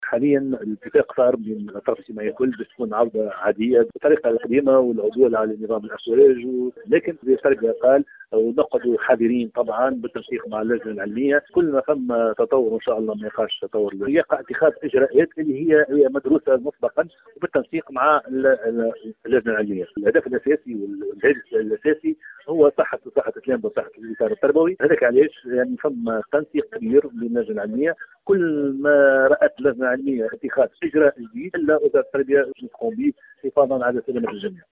Dans une déclaration accordée à Tunisie numérique